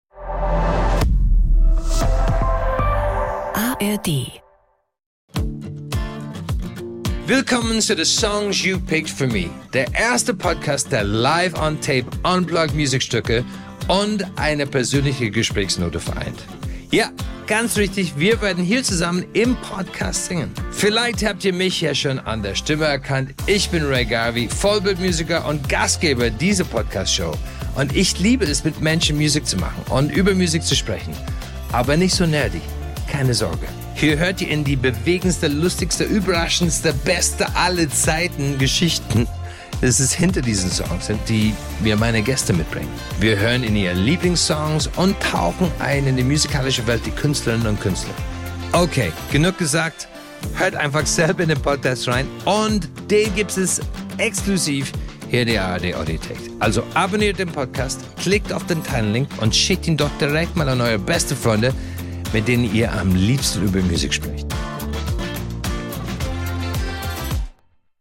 Host und Musiker Rea Garvey lädt deutschsprachige Promis und Künstler:innen zu sich ein, um gemeinsam Musik zu hören, zu quatschen und zu singen. Es geht um emotionale Erlebnisse im Leben der Gäste, wichtige Momente in ihren Karrieren und natürlich um Lieblingssongs und ganz viel um Musik!
In jeder Folge gibt es zwei exklusive Unplugged-Songs, die die Gäste für sich und Rea rausgepickt haben und die ihr nur im Podcast hören könnt.